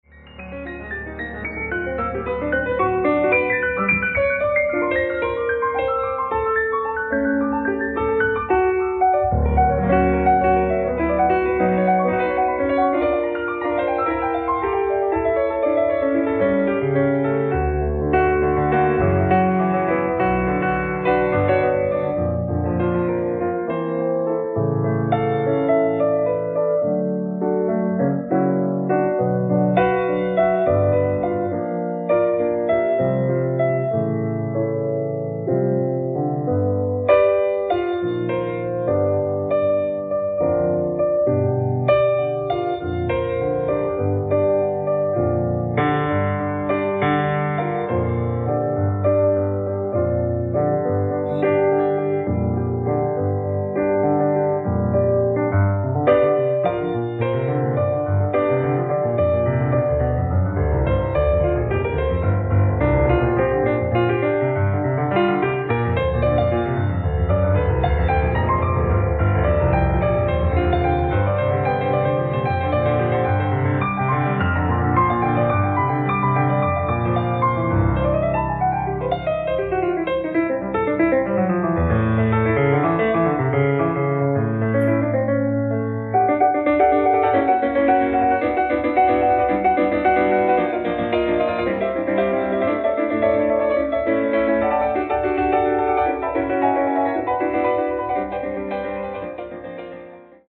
3.500円 ライブ・アット・ドメーヌ・ドー、モンペリエ、フランス 07/09/2025 最新ソロ・パフォーマンス！！
※試聴用に実際より音質を落としています。